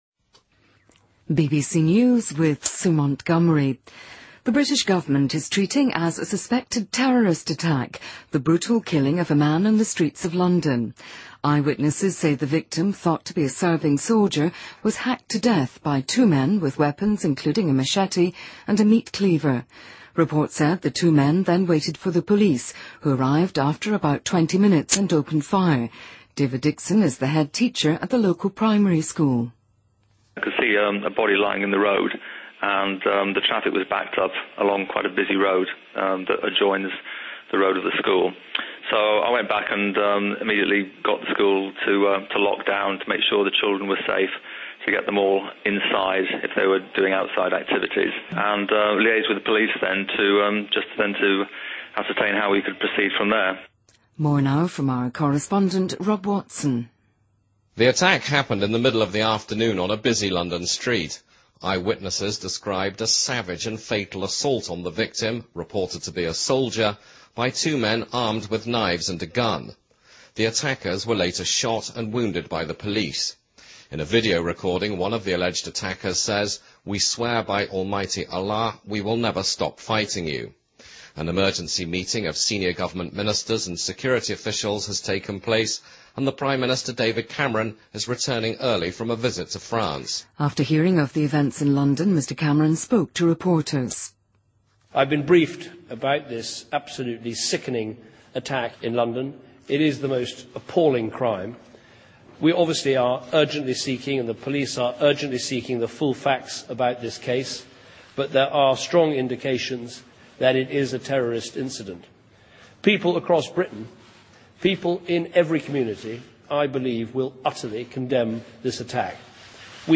BBC news,2013-05-23